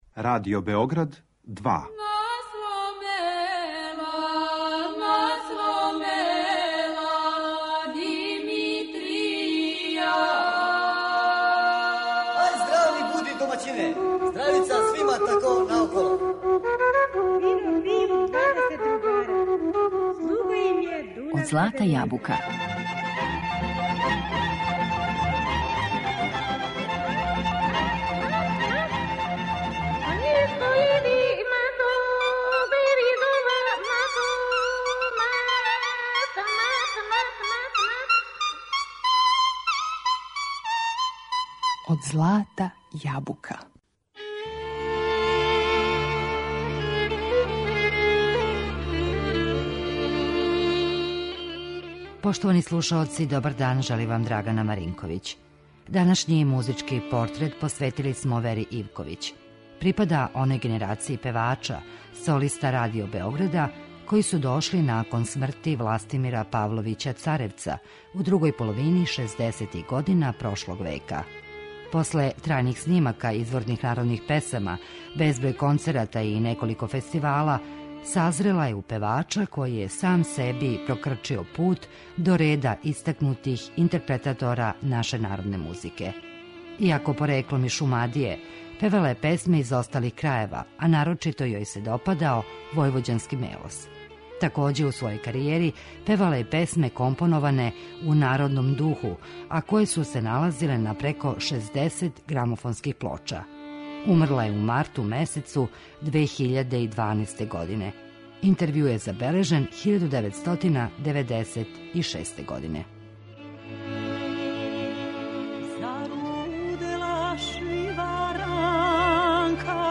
музички портрет
а у својој веома плодној каријери изводила је и песме које су компоноване у народном духу.